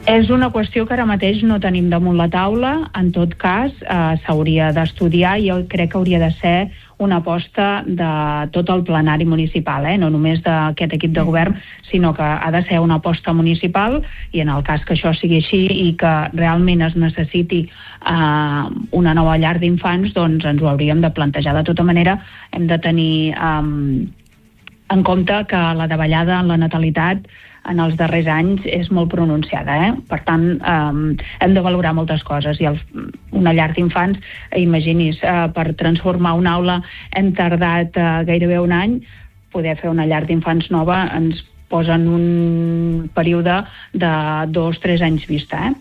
Entrevistes Supermatí
I per parlar d’aquesta ampliació ens ha visitat al Supermatí la regidora d’educació de l’Ajuntament de Palamós, Yolanda Aguilar.